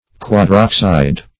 Quadroxide \Quad*rox"ide\, n.